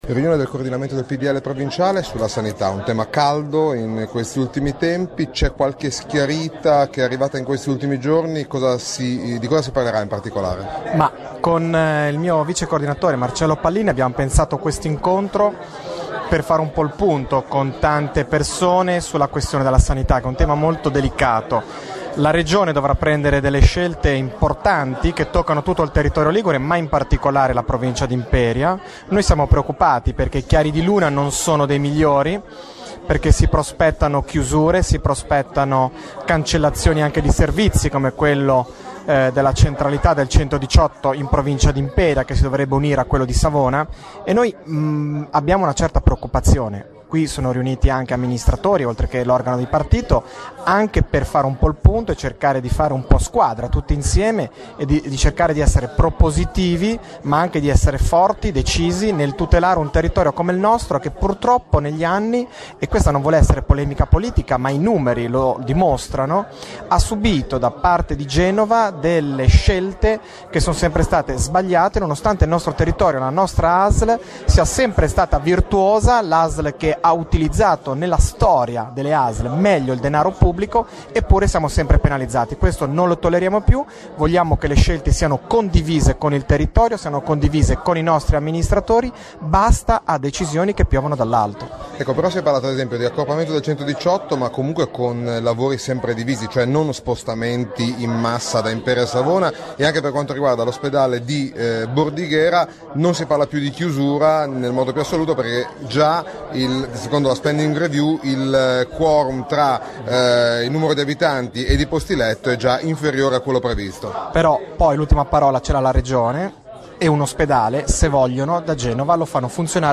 Il commento alla situazione di Marco Scajola cliccando